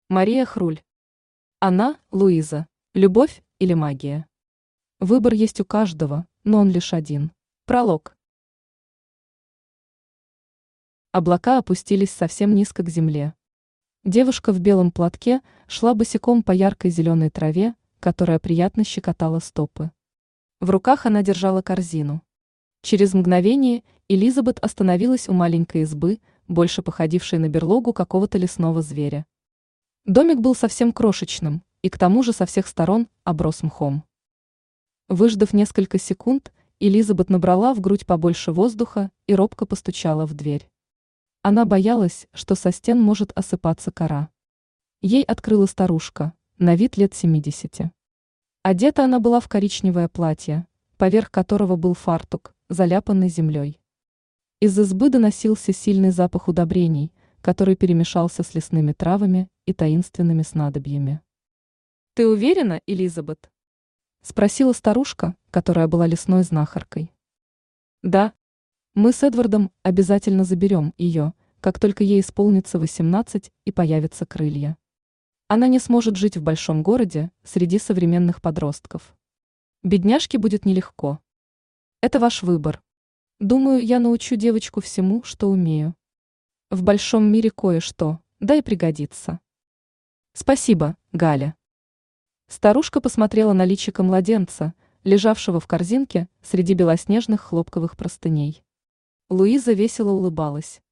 Аудиокнига Она – Луиза | Библиотека аудиокниг
Aудиокнига Она – Луиза Автор Мария Денисовна Хруль Читает аудиокнигу Авточтец ЛитРес.